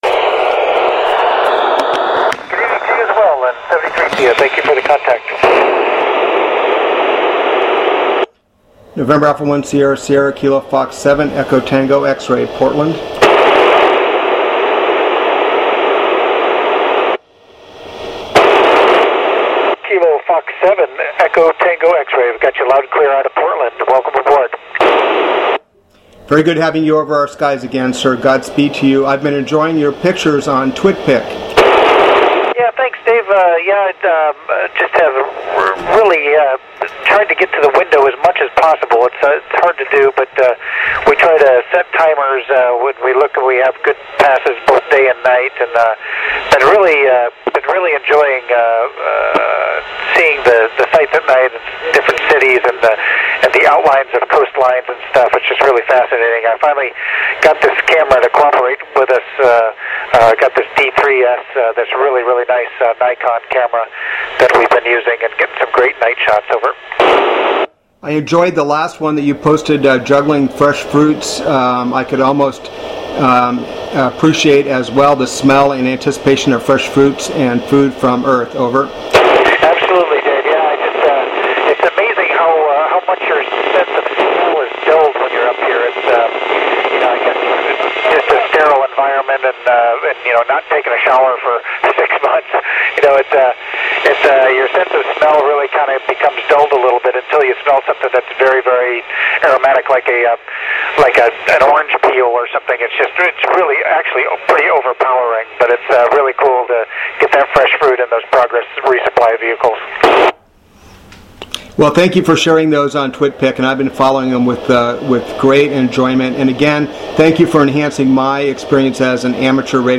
Great QSO with Col Wheelock while he discusses the joy of fresh fruit delivered to ISS, the fun of taking some awesome photos and his return to planet earth which was cut short at the end of the pass.
VOICE
VHF (other)